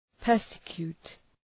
Προφορά
{‘pɜ:rsə,kju:t}